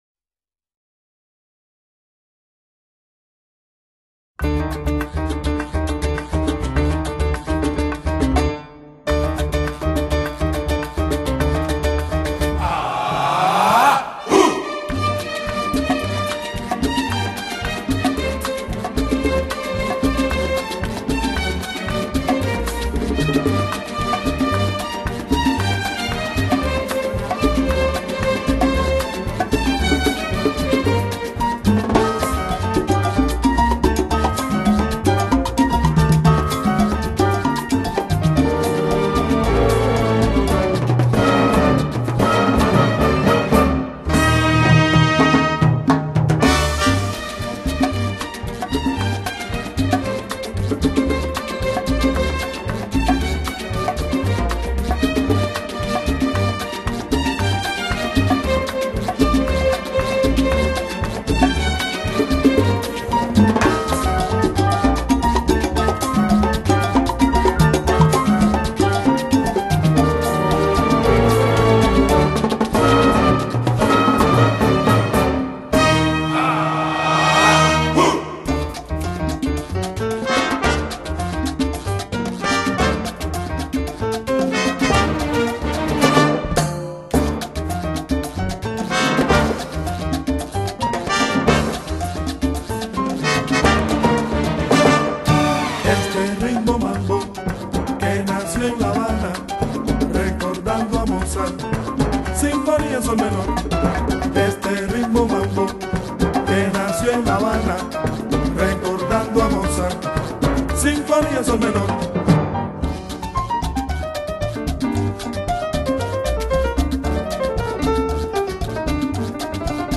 Classical, Jazz, Latino, World & Cuban